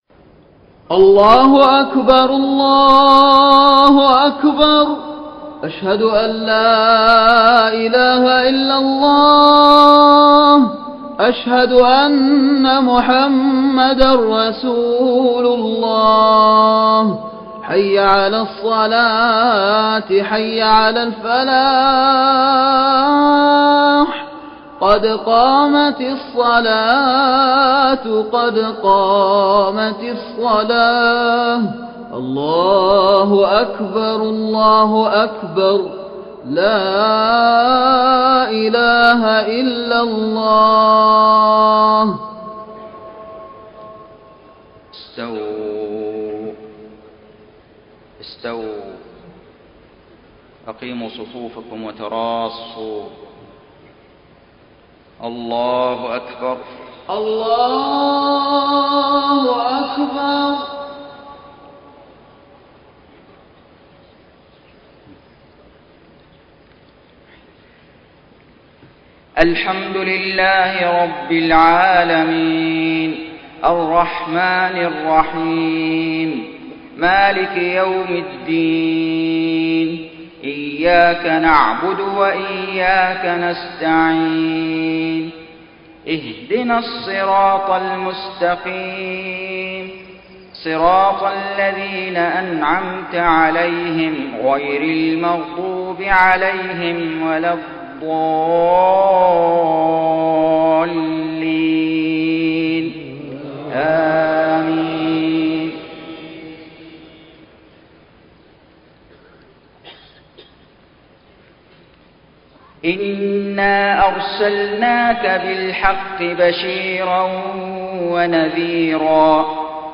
صلاة الفجر 20 ذو القعدة 1432هـ من سورة فاطر 24-39 > 1432 🕋 > الفروض - تلاوات الحرمين